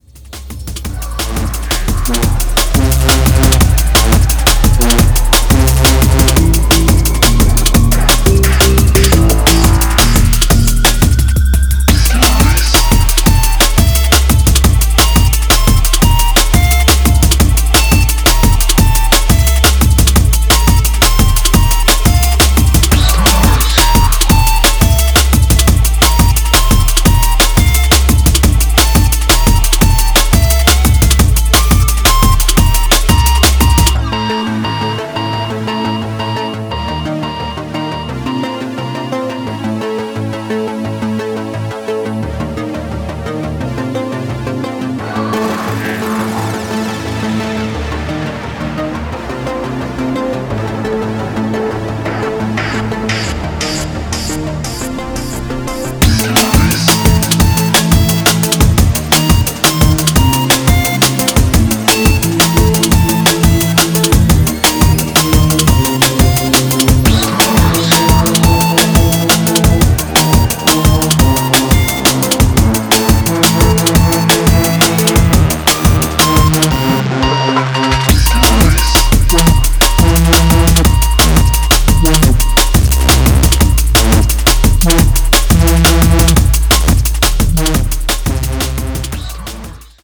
Styl: Drum'n'bass, Jungle/Ragga Jungle Vyd�no